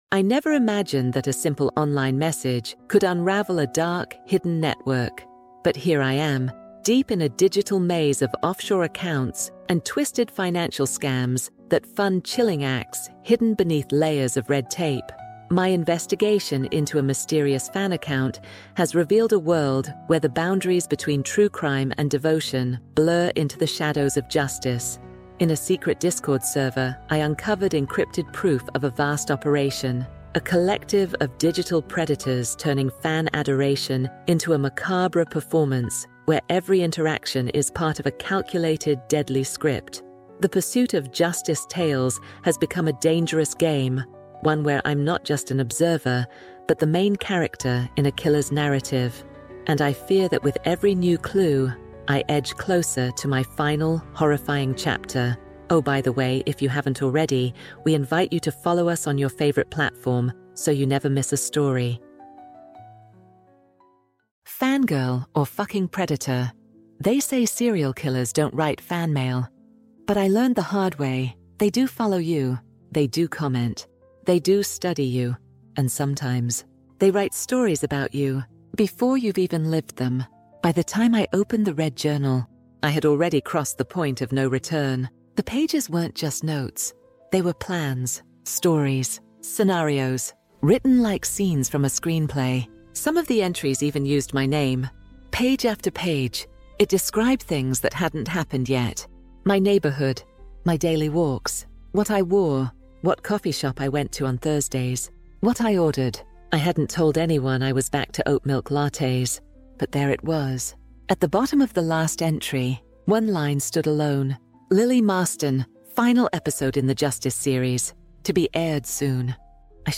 True Crime | Shadows of Justice EP2 | Fan Girl or F**king Predator | Audiobook